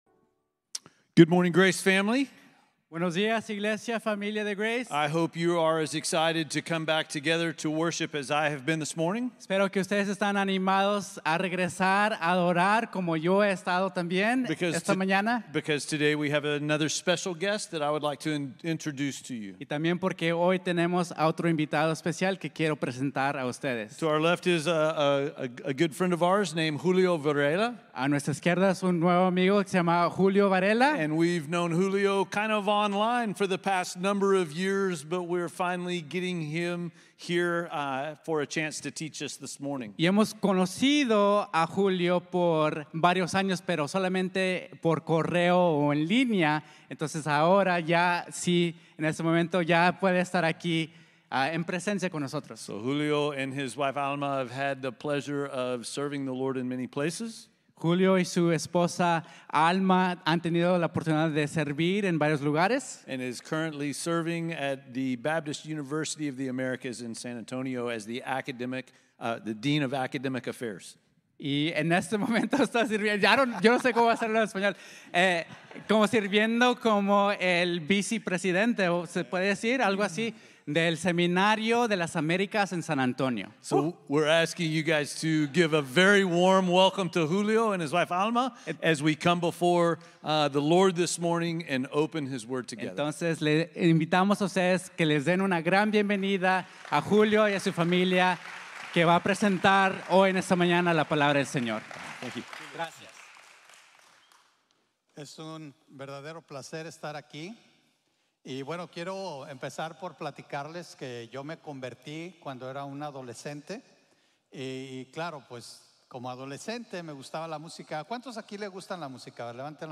Principios para nuestra vida de oración | Sermon | Grace Bible Church